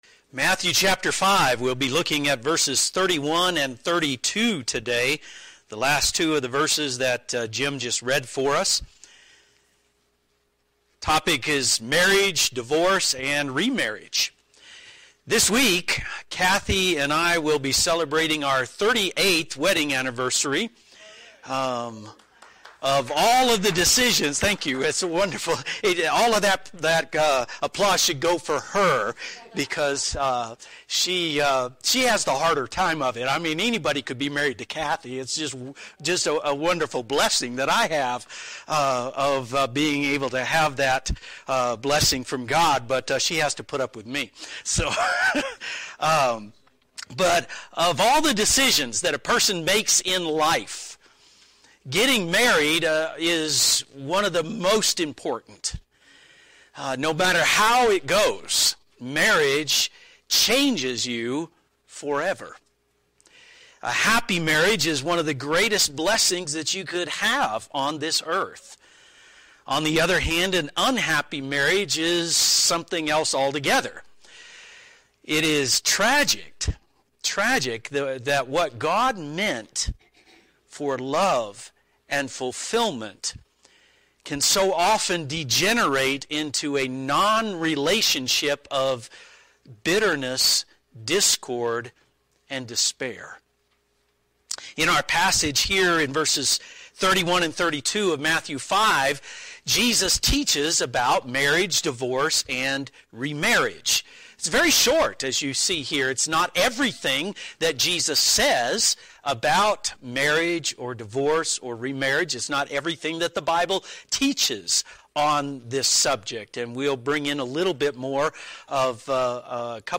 If you have a divorce in your past this sermon is not meant to pick on you.